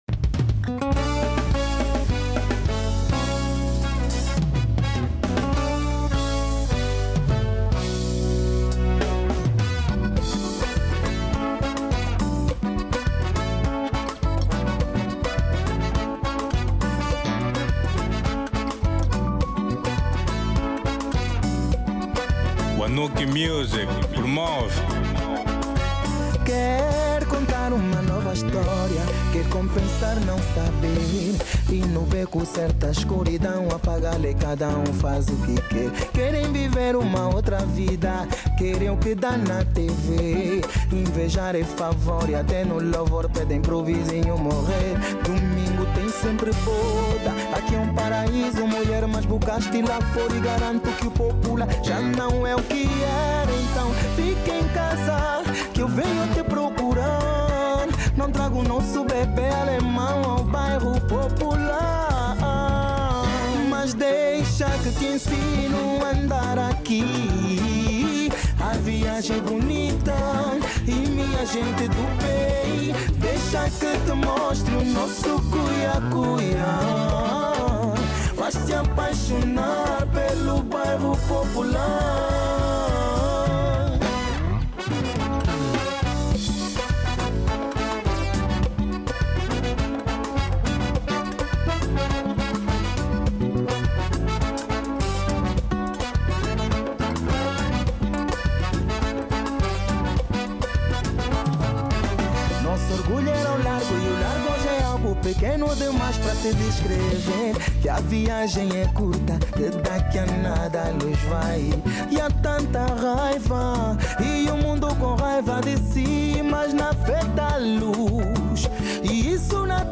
Genero: Semba